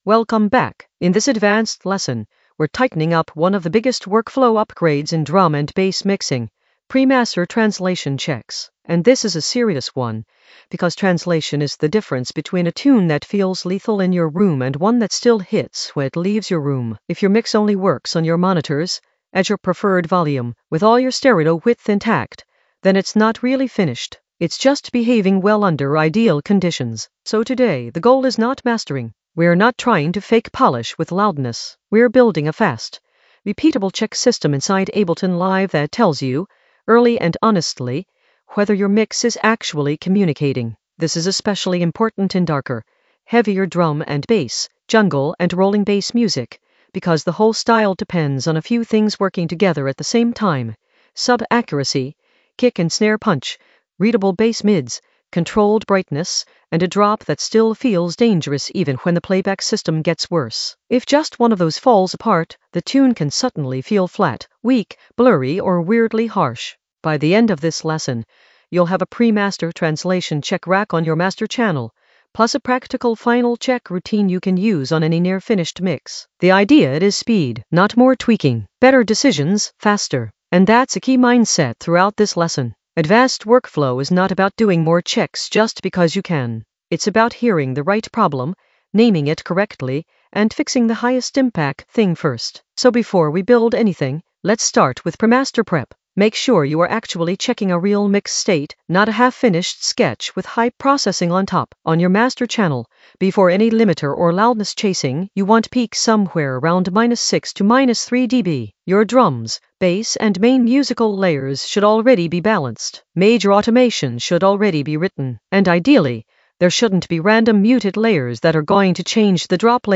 An AI-generated advanced Ableton lesson focused on Pre-master translation checks for faster workflow in the Mixing area of drum and bass production.
Narrated lesson audio
The voice track includes the tutorial plus extra teacher commentary.